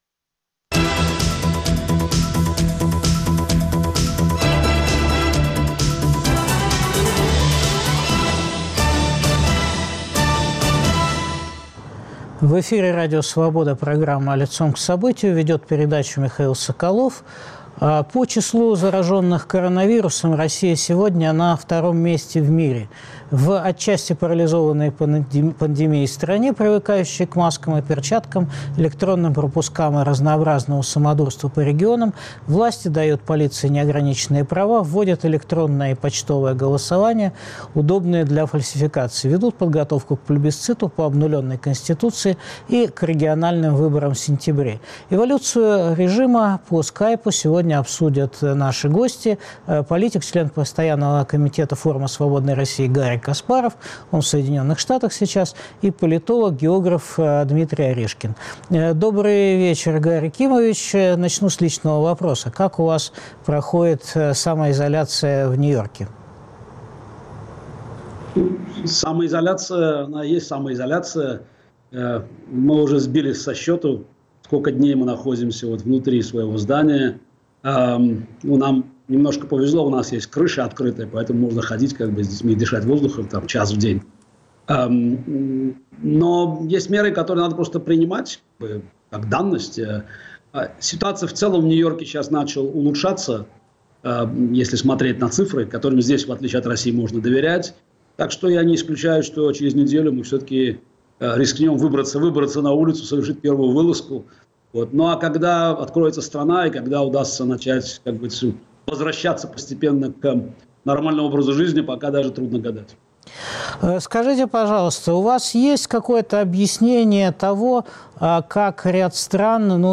Выйдет ли режим Путина из эпидемического кризиса окрепшей полицейской диктатурой? Эволюцию режима обсуждают политик, член постоянного комитета Форума свободной России Гарри Каспаров и политолог, географ Дмитрий Орешкин.